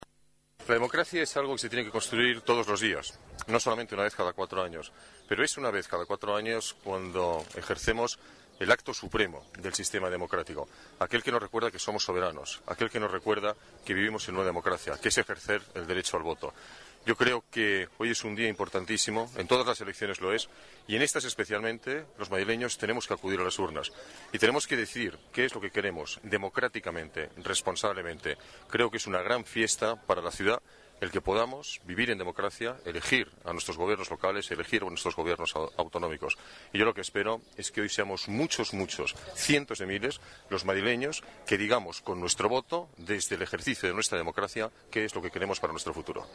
Nueva ventana:Declaraciones de Alberto Ruiz-Gallardón en su colegioo electoral